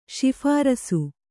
♪ śi'hārasu